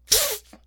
pain1.ogg